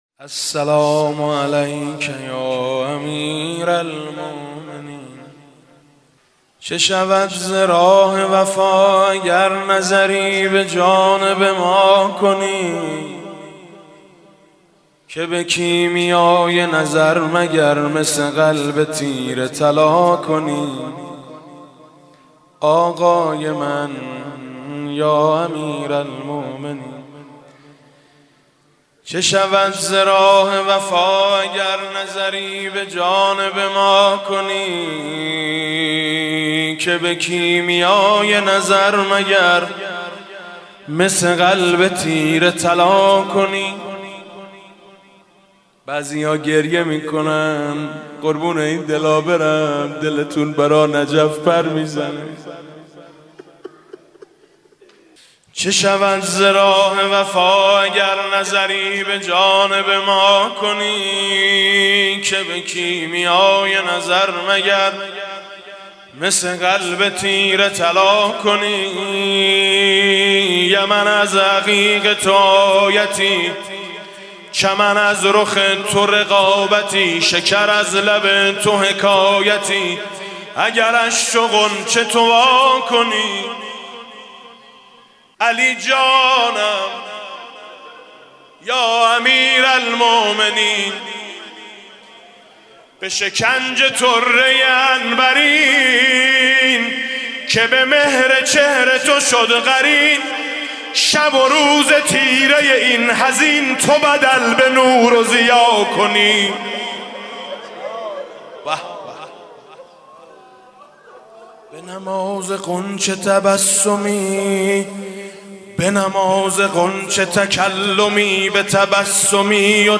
مدح: چه شود ز راه وفا اگر نظر به جانب ما کنی